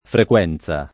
[ frek U$ n Z a ]